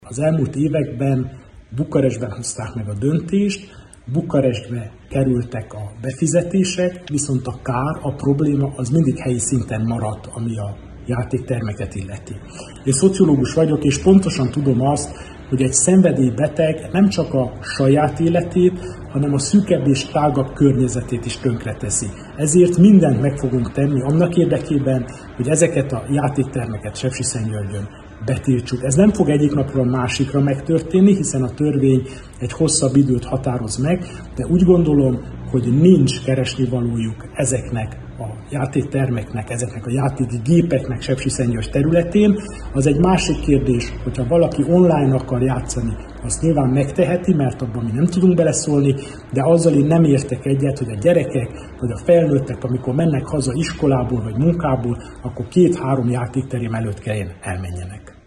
Antal Árpád polgármester hétfői sajtótájékoztatóján hangsúlyozta: a közigazgatási reformcsomag egyik pozitív hozadéka, hogy a szerencsejáték-termek működtetéséhez ezentúl a helyi önkormányzat engedélyére lesz szükség.